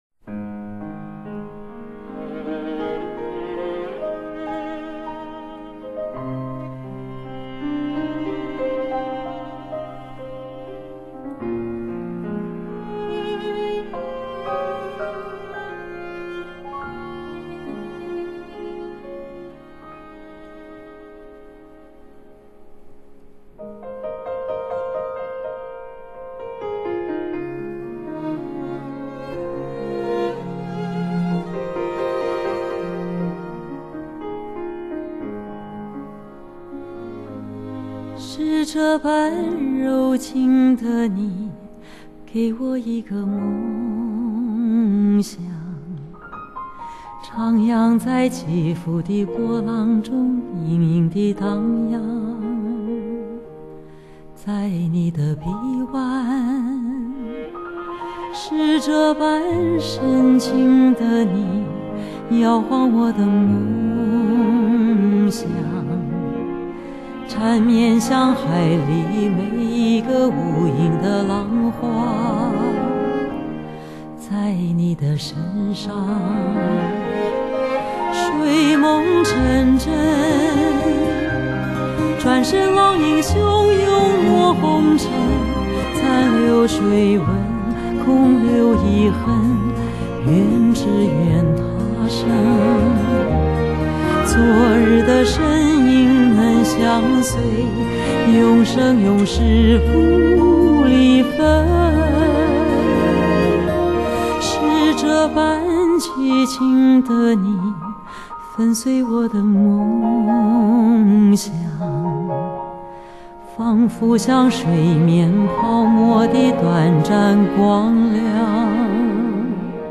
優美絕倫的歌聲
全程現場演唱、收音、錄音